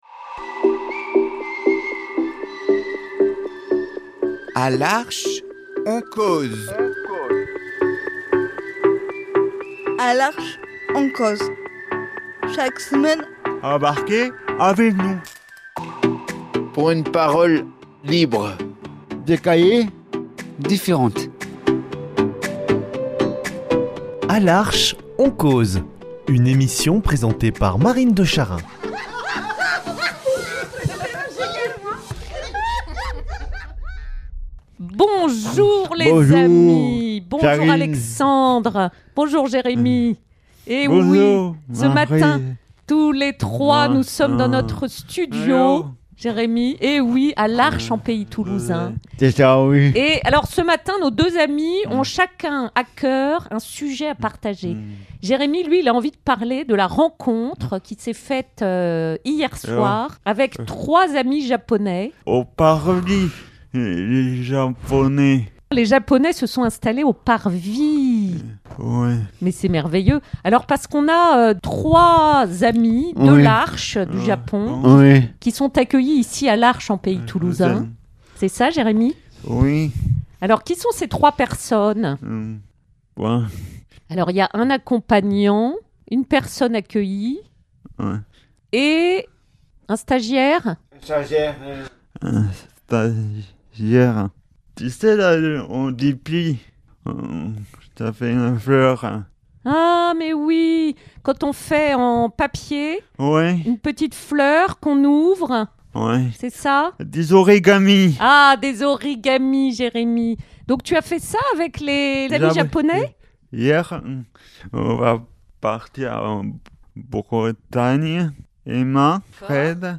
Faire sa valise, partir en camion, rejoindre des amis à l’autre bout de la France et découvrir une région, tout est joie à chaque étape ! Echanges libres au micro sur tout ce qui les anime et qui rend nos vies belles !